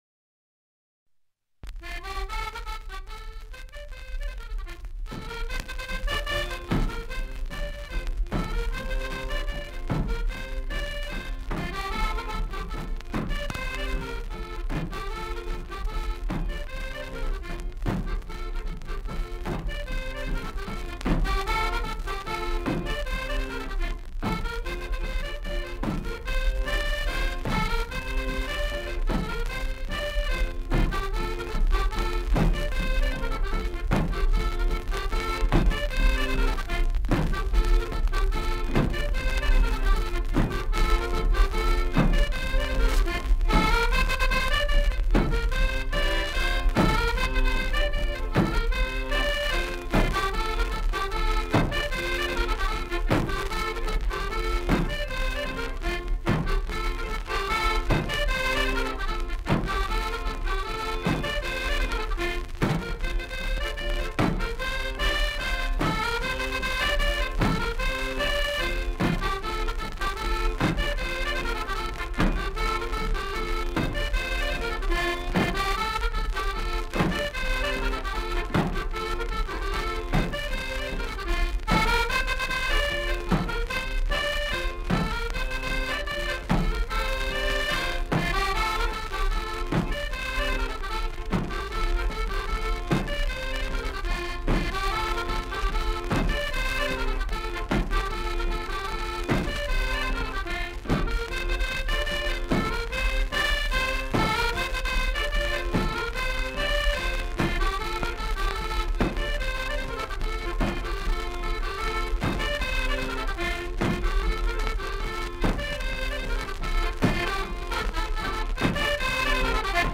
Genre : morceau instrumental
Instrument de musique : accordéon chromatique
Danse : bourrée d'Ariège
Notes consultables : Enregistrement d'un 45T.